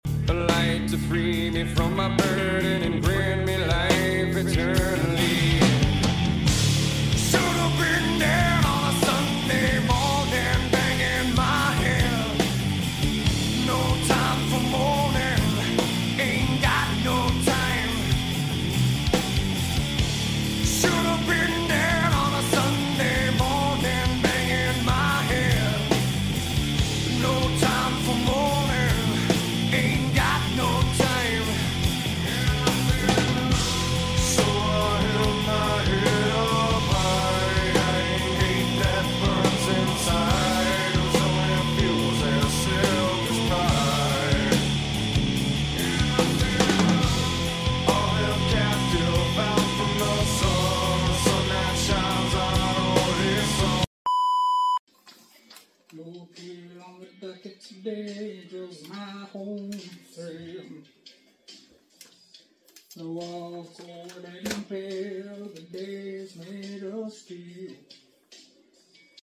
Scania S580 supercharged diesel 650hp sound effects free download